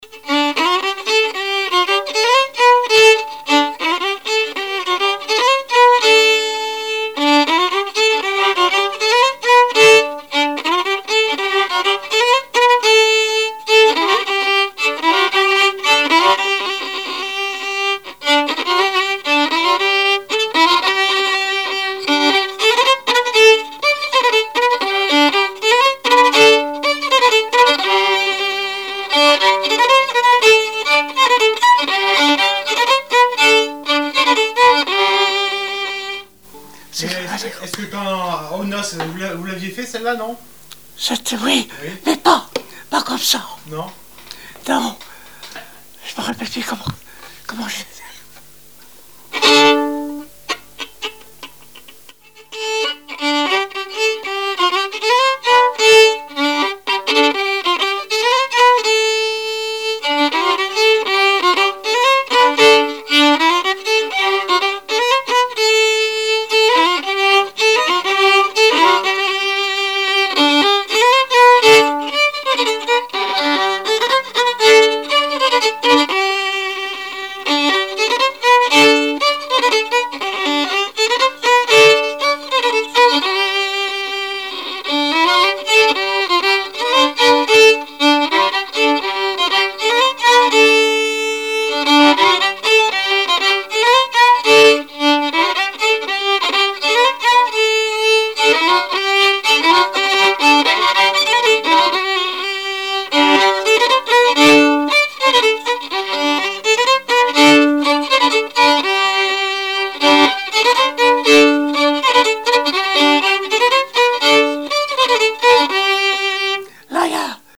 danse : ronde : grand'danse
Répertoire musical au violon
Pièce musicale inédite